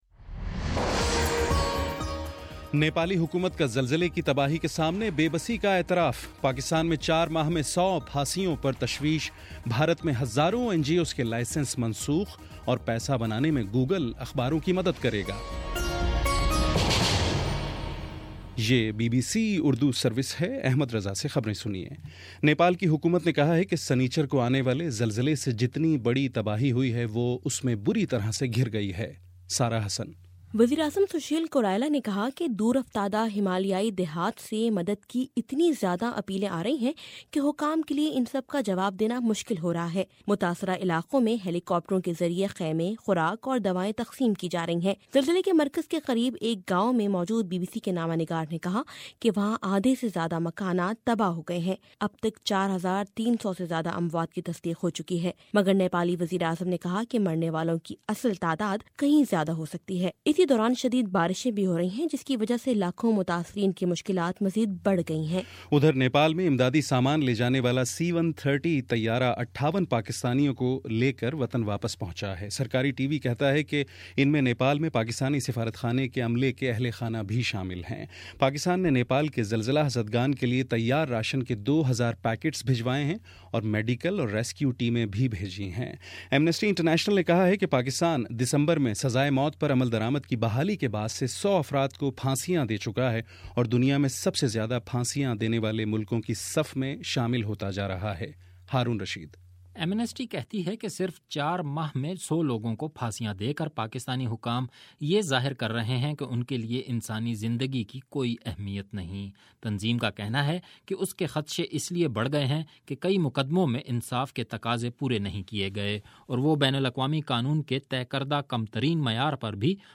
اپریل 28: شام چھ بجے کا نیوز بُلیٹن